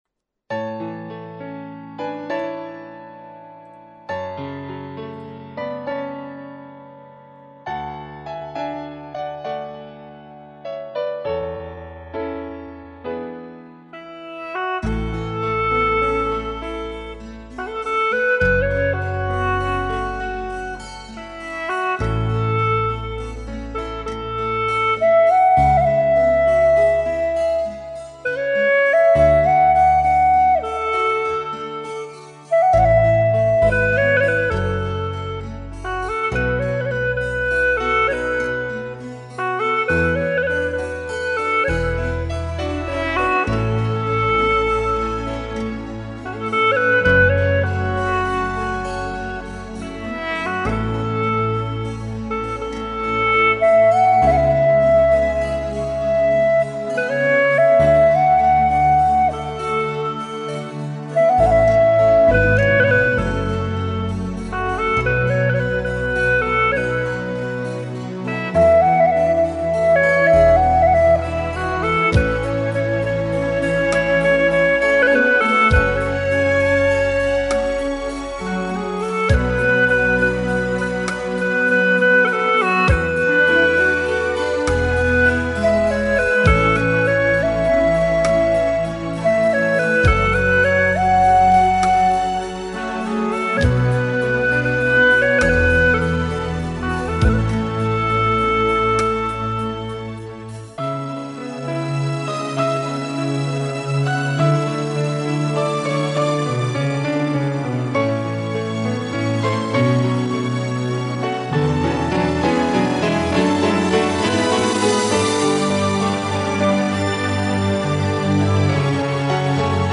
调式 : A-bB 曲类 : 流行